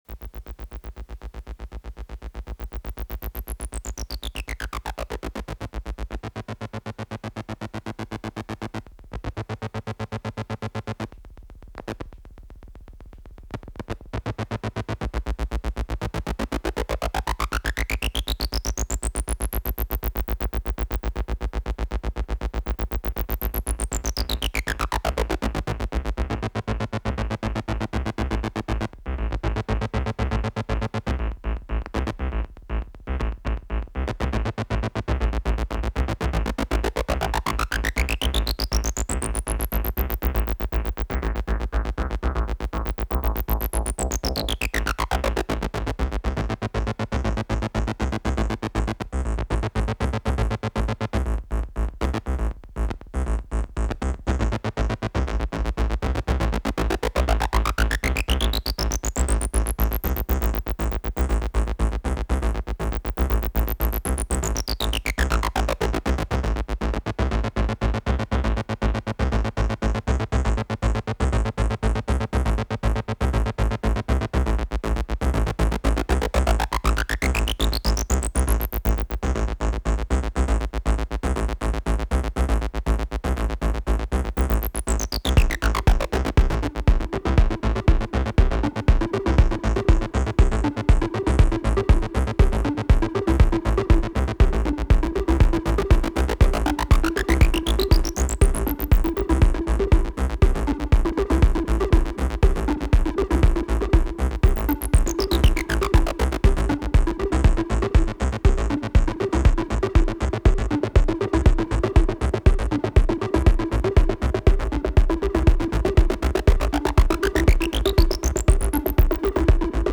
THE MEDIA ART FESTIVAL IN AUGSBURG!